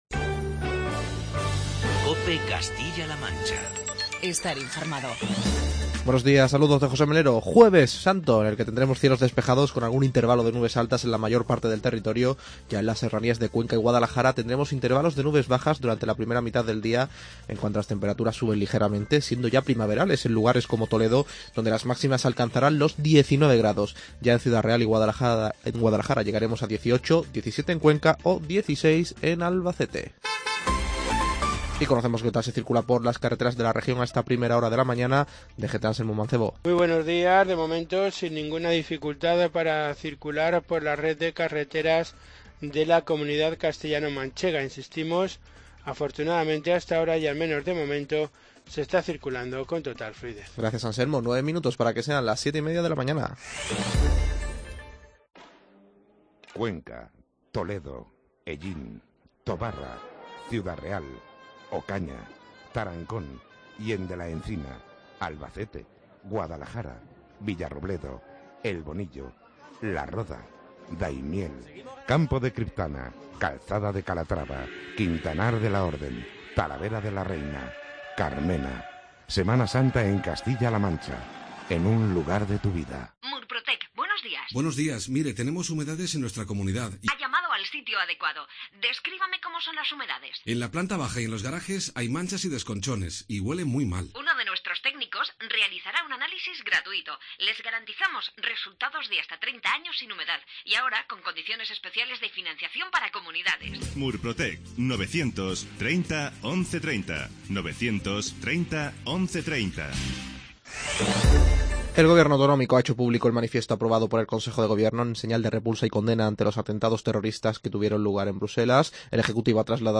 Informativo regional